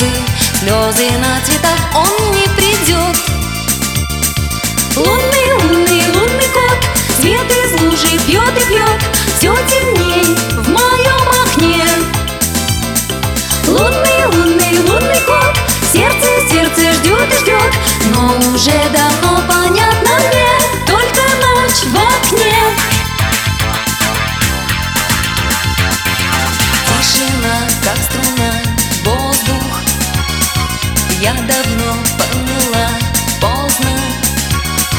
Жанр: Поп / Русские
# Pop